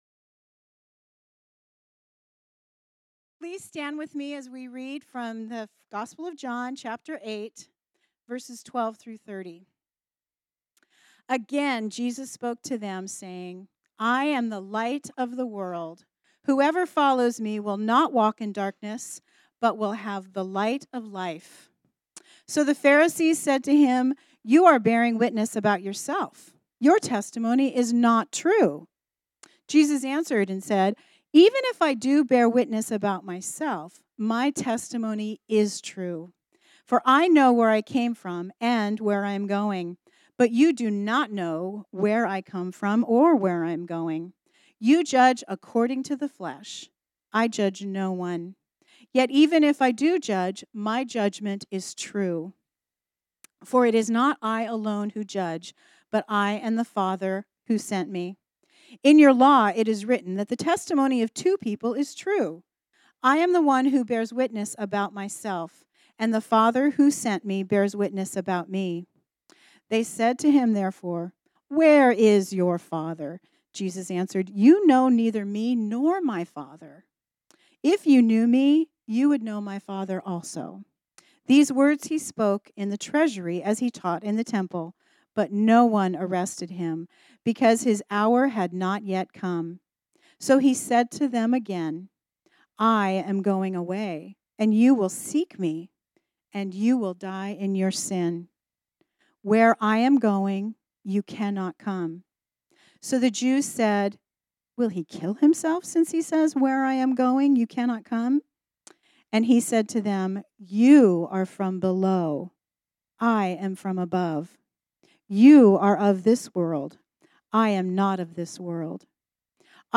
This sermon was originally preached on Sunday, January 5, 2020.